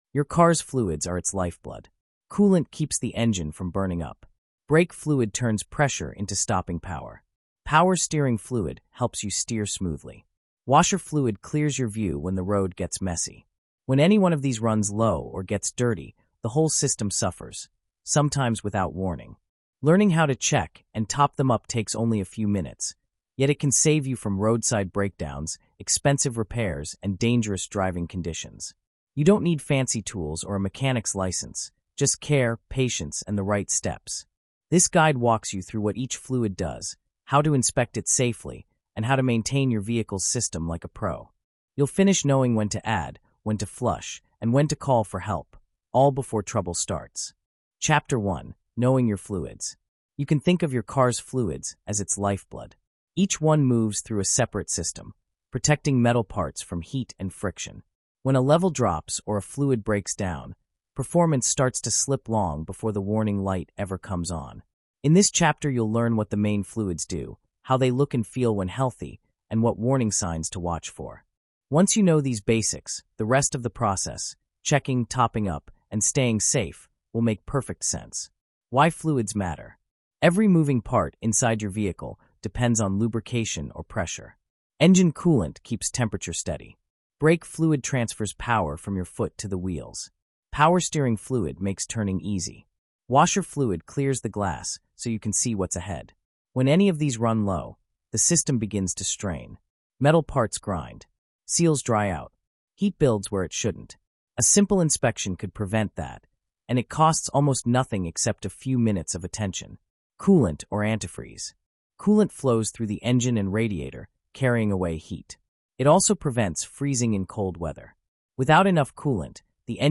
Fluid Checks takes listeners inside the garage to learn how to inspect, top up, and maintain these vital systems with confidence and care. Told in a clean, step-by-step narrative, this hands-on episode turns a simple task into a skillset every driver can master.
Recorded in a cinematic, workshop-inspired tone, Fluid Checks combines the clarity of a how-to guide with the grounded energy of a gearhead’s audio manual.